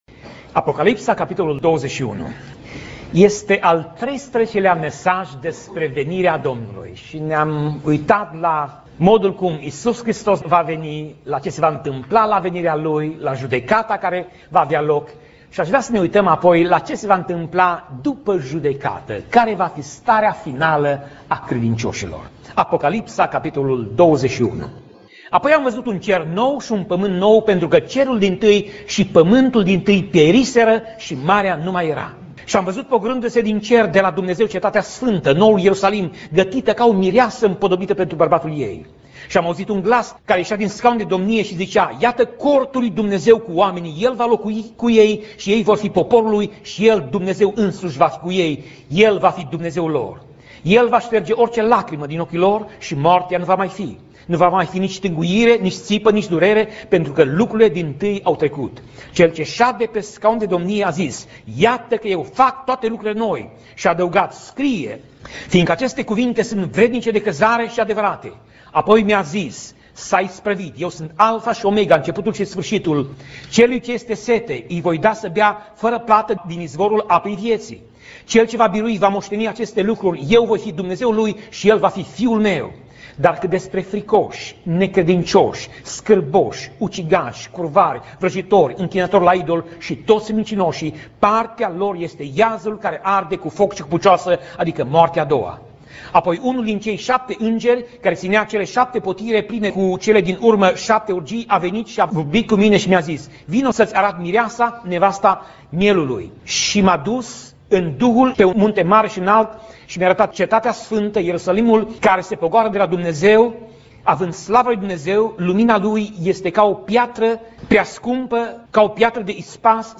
Pasaj Biblie: Apocalipsa 21:1 - Apocalipsa 21:27 Tip Mesaj: Predica